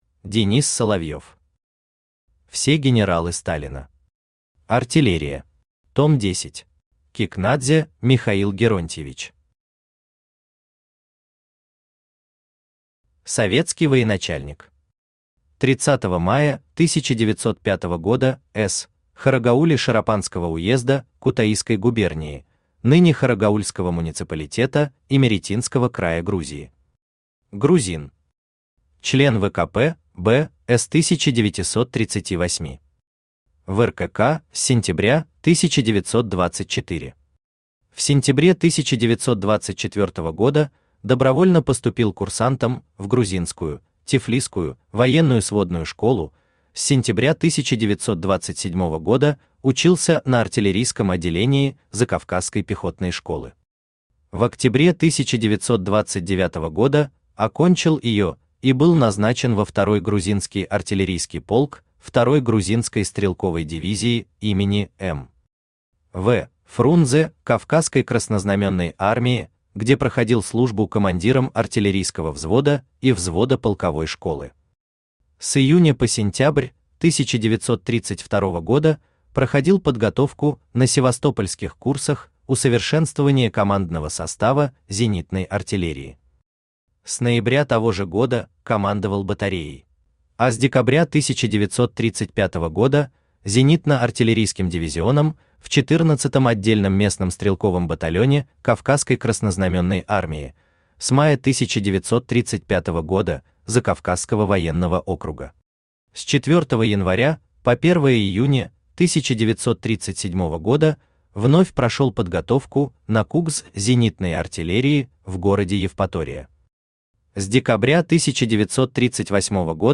Аудиокнига Все генералы Сталина. Артиллерия. Том 10 | Библиотека аудиокниг
Том 10 Автор Денис Соловьев Читает аудиокнигу Авточтец ЛитРес.